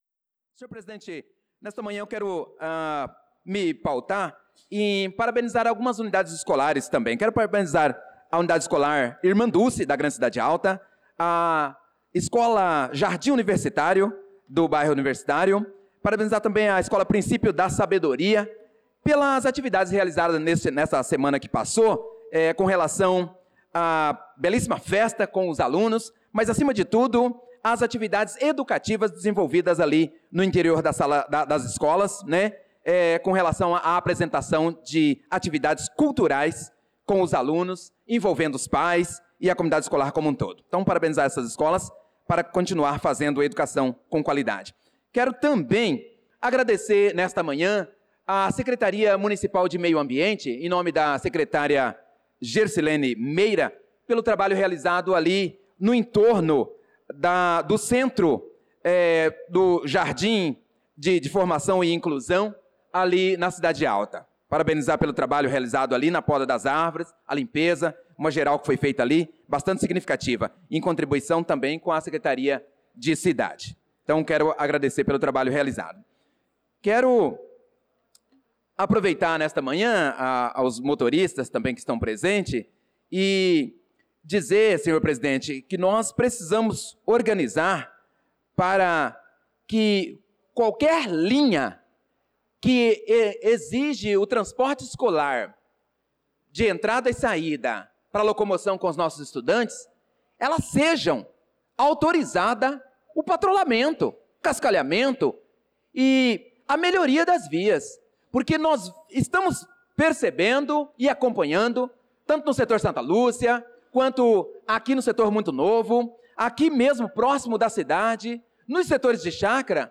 Pronunciamento do vereador Prof. Nilson na Sessão Ordinária do dia 23/06/2025.